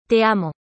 Listen to the correct pronunciation here: